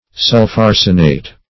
Sulpharsenate \Sulph*ar"se*nate\, n.